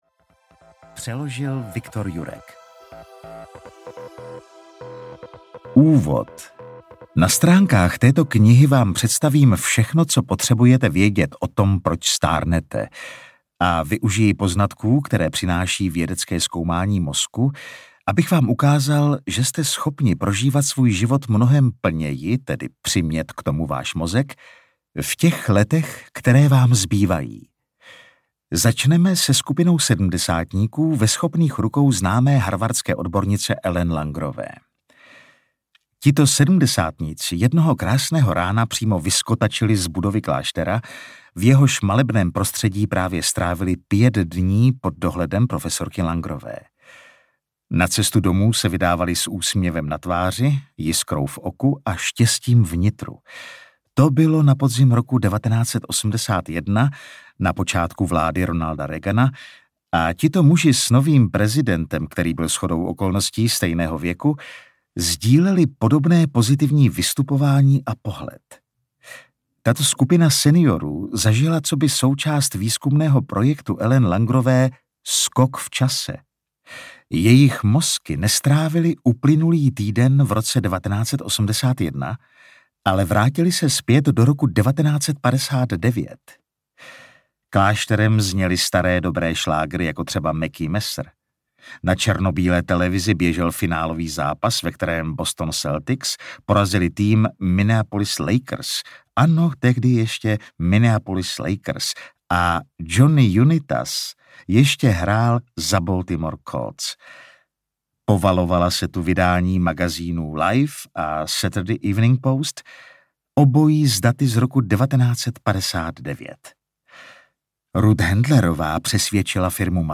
Nestárnoucí mozek audiokniha
Ukázka z knihy